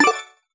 button-generic-select.wav